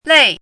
lèi
拼音： lèi
注音： ㄌㄟˋ
lei4.mp3